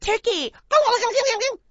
turkey_gibberish.wav